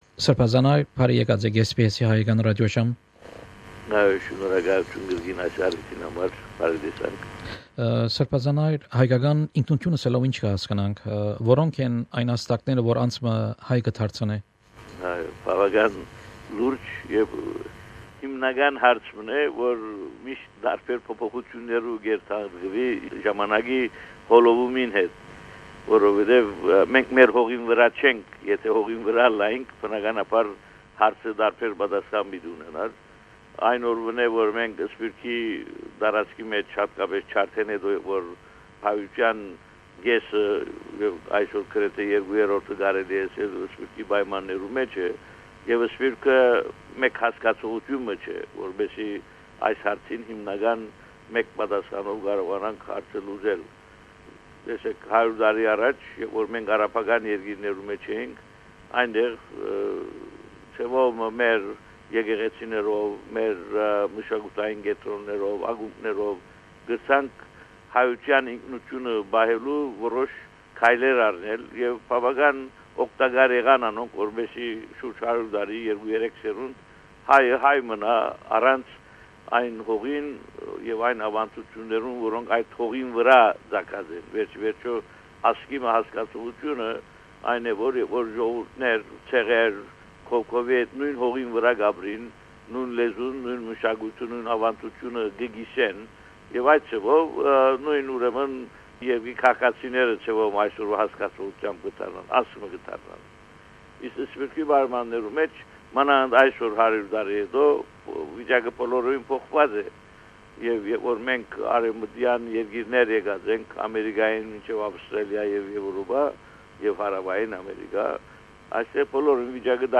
Interview with Bishop Haygazoun Najarian, Primate of Armenians in Australia & New Zealand on Armenian identity.